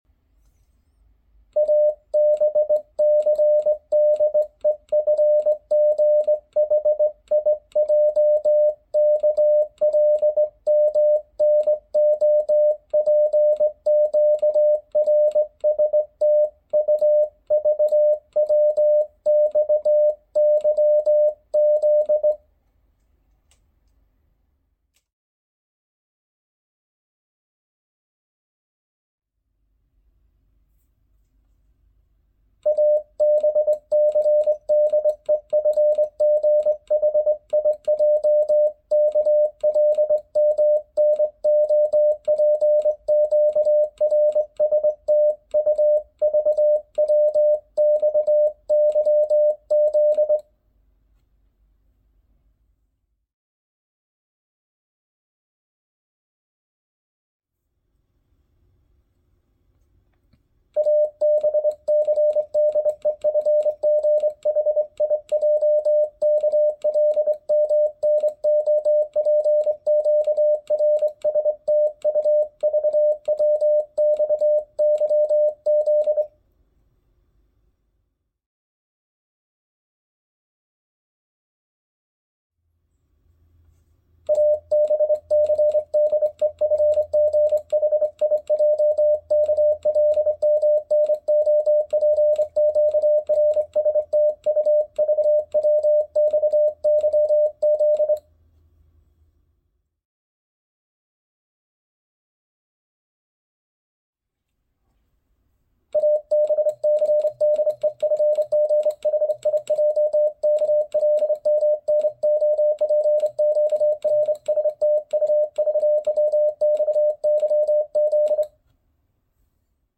Sending A Z in Morse code sound effects free download
Sending A-Z in Morse code at various speeds using a Begali Intrepid bug key, ranging from 17 to 30 words per minute (WPM).